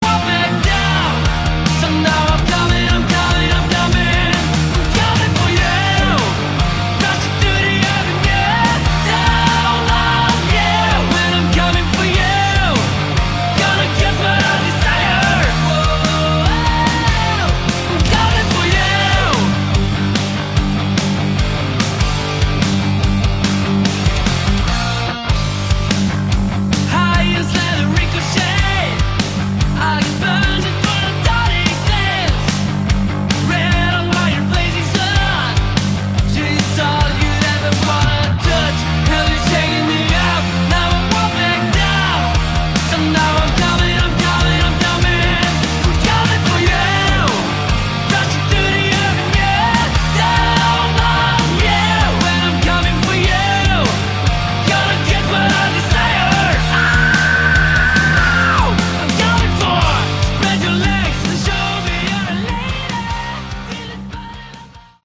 Category: Melodic Metal
vocals, guitar
bass
drums